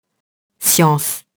science [sjɑ̃s]